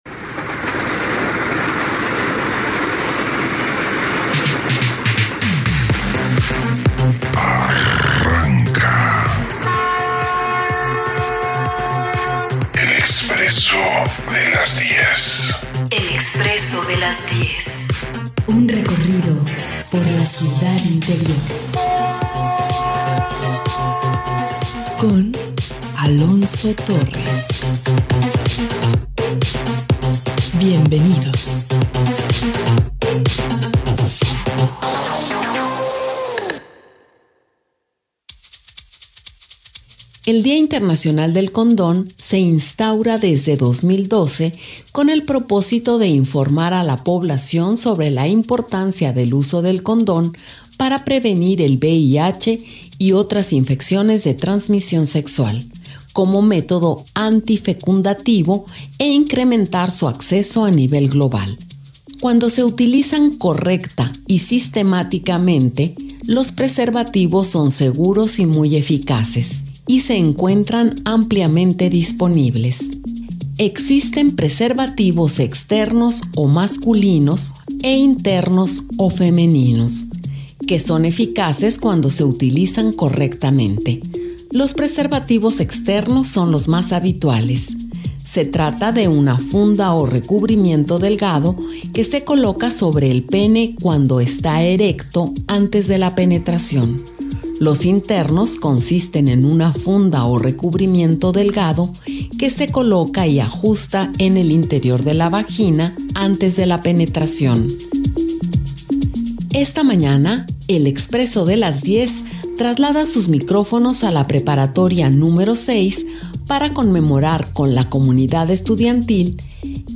En este podcast de El Expresso de las 10 escucha la transmisión desde la preparatoria número 6 donde celebramos con su comunidad estudiantil, El Día internacional del condón… Desde Miravalle.